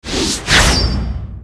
SFX大刀二连斩声音音效下载
SFX音效